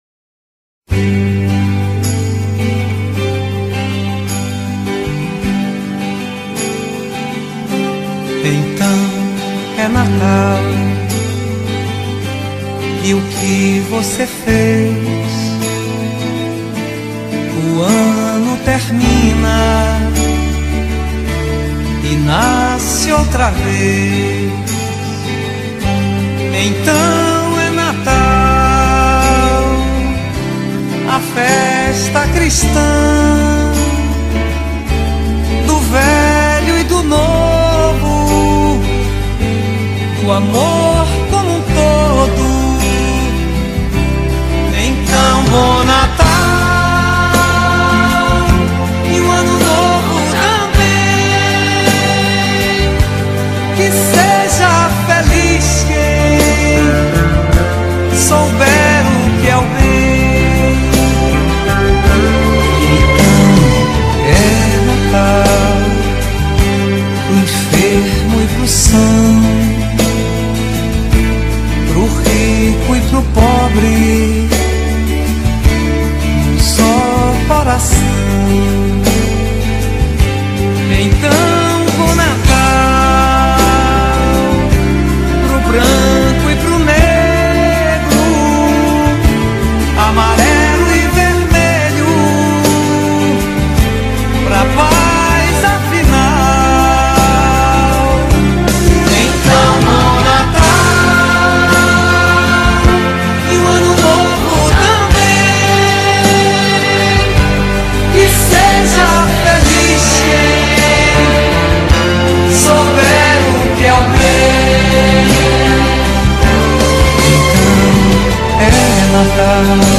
2024-12-16 21:11:31 Gênero: MPB Views